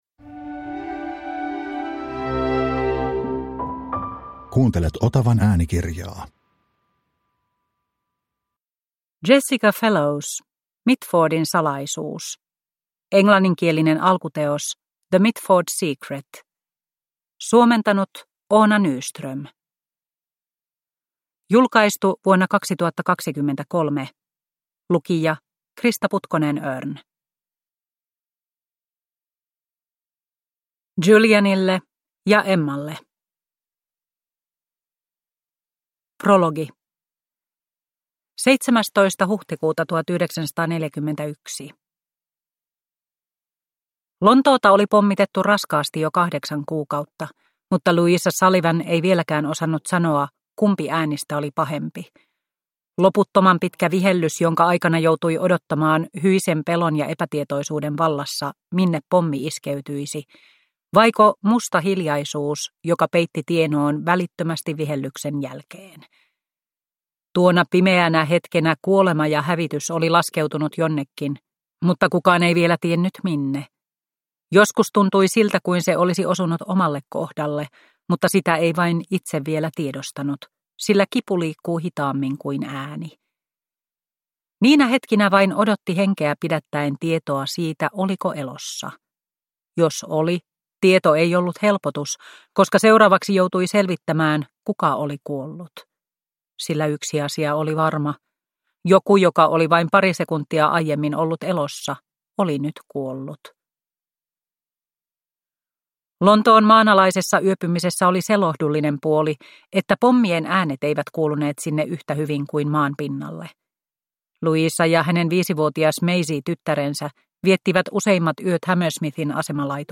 Mitfordin salaisuus – Ljudbok – Laddas ner